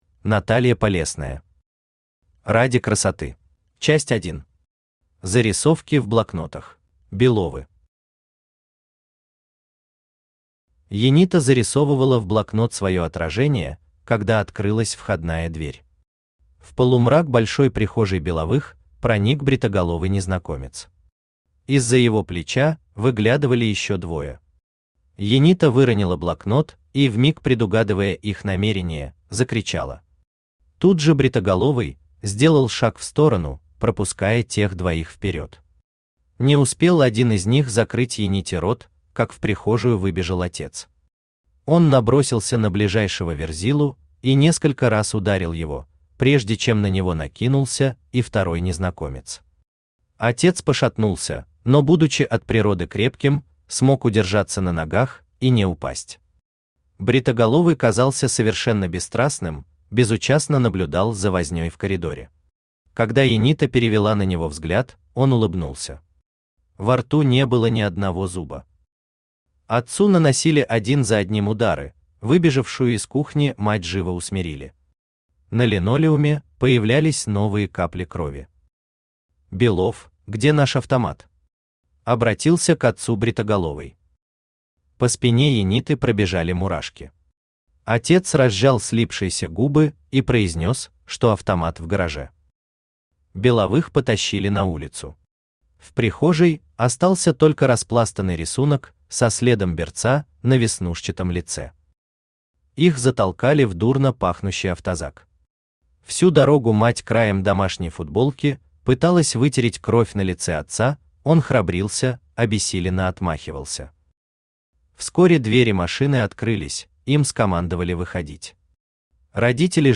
Aудиокнига Ради красоты Автор Наталья Полесная Читает аудиокнигу Авточтец ЛитРес.